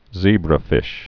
(zēbrə-fĭsh)